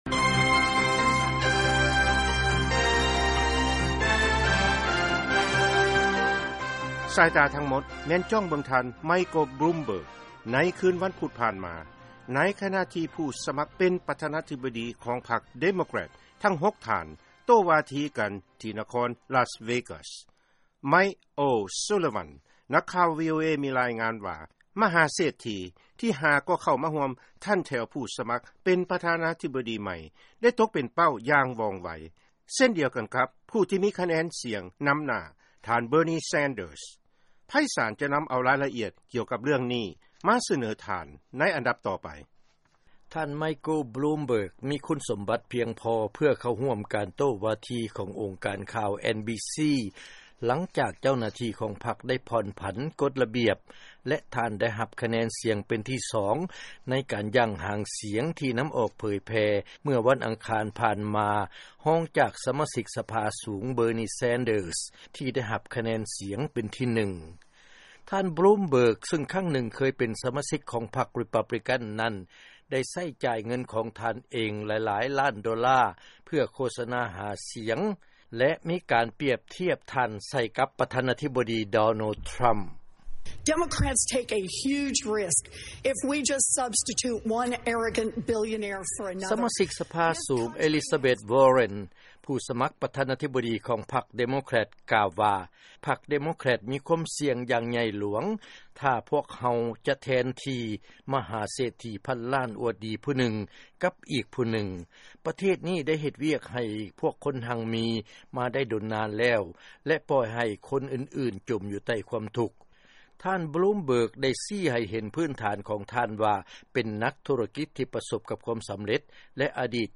ເຊີນຟັງລາຍງານ ທ່ານບລູມເບີກ ຕົກເປັນເປົ້າ ໃນລະຫວ່າງ ການໂຕ້ວາທີ ຜູ້ສະໝັກປະທານາທິບໍດີ ພັກເດໂມແຄຣັດ